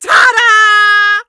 chester_ulti_vo_07.ogg